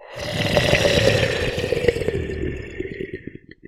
spawners_mobs_mummy_neutral.1.ogg